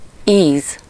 Ääntäminen
Synonyymit palais appétit Ääntäminen France: IPA: [gu] Tuntematon aksentti: IPA: /ɡu/ Haettu sana löytyi näillä lähdekielillä: ranska Käännös Ääninäyte 1. íz 2. ízlés Suku: m .